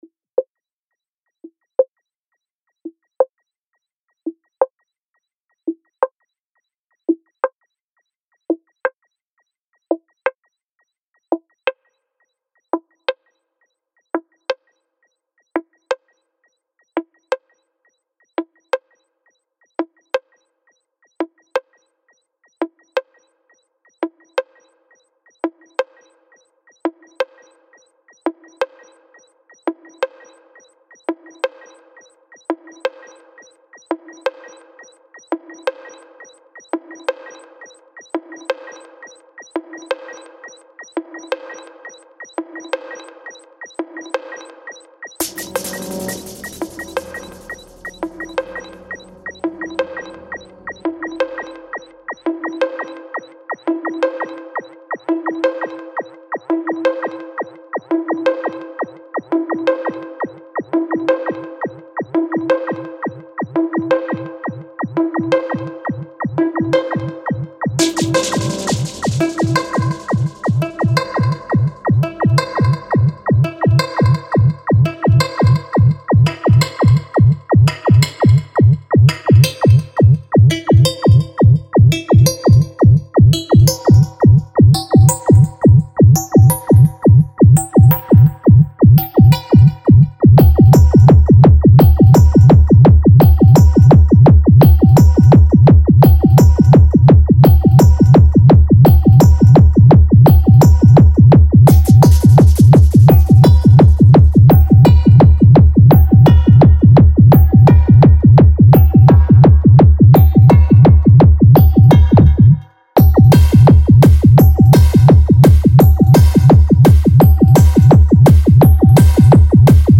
Tekno Mental Descàrregues i reproduccions